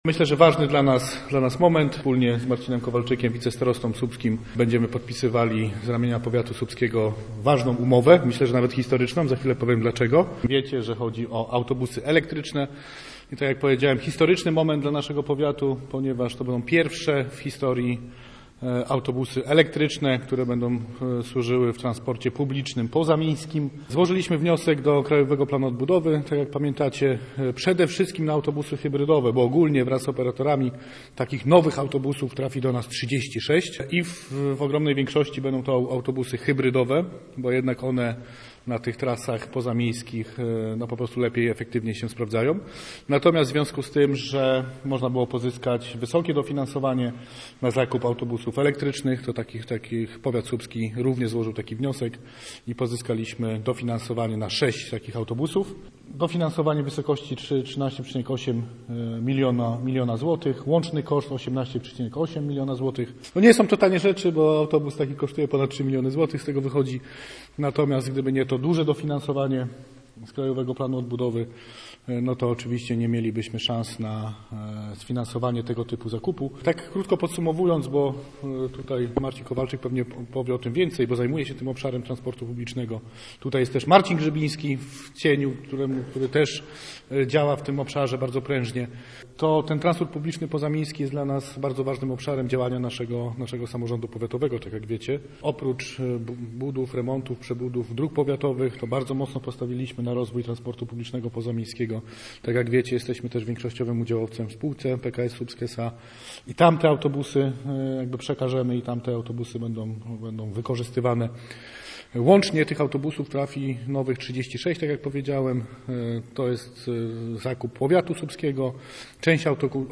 Posłuchaj materiału reportera Radia Gdańsk: https